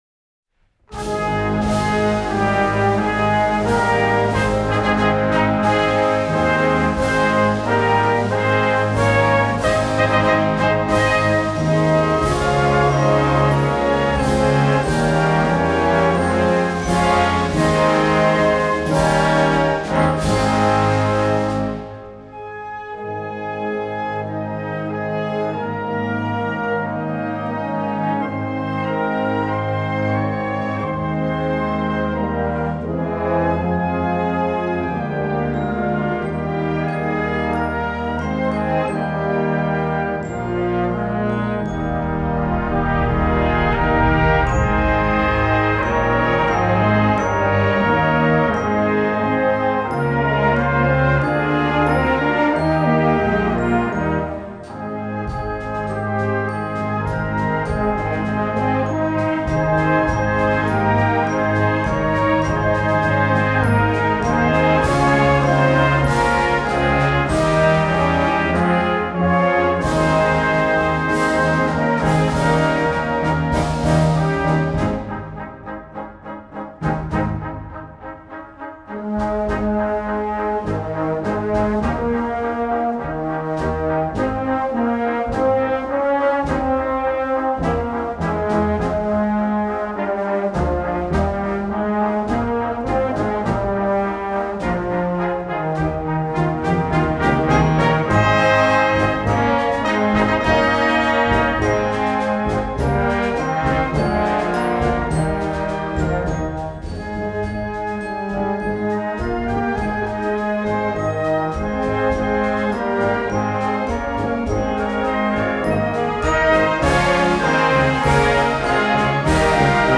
Gattung: Weihnachtsmusik für Blasorchester
Besetzung: Blasorchester